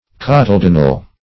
Search Result for " cotyledonal" : The Collaborative International Dictionary of English v.0.48: Cotyledonal \Cot`y*led"on*al\ (k?t`?-l?d"?n-al), a. Of, pertaining to, or resembling, a cotyledon.
cotyledonal.mp3